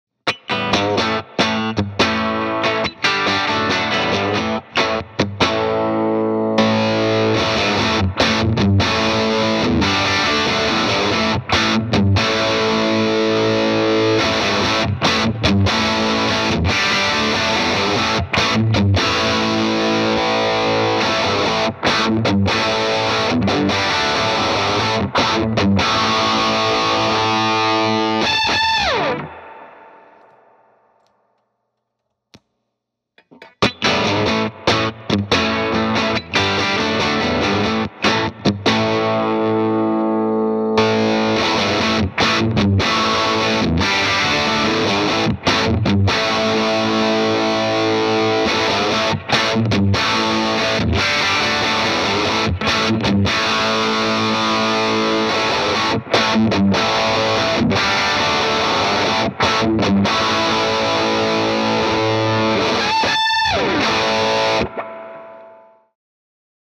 🔥 VOX Power Burst sound effects free download By voxamplification 0 Downloads 1 days ago 66 seconds voxamplification Sound Effects About 🔥 VOX Power Burst - Mp3 Sound Effect 🔥 VOX Power Burst - Valve Boost Pedal • 1 Knob; Three frequency modes • Tube-driven character • Perfect for solos or shaping your drive tone Learn More at the Link in Bio.